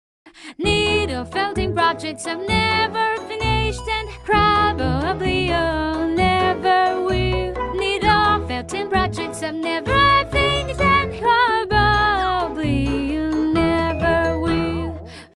Here's a bag of my needlefelting WIP's. Some around a decade old. As a musical bc why not! (it's a text-to-speech pro feature in CapCut, that I just discovered)